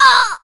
jess_hurt_02.ogg